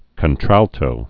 (kən-trăltō)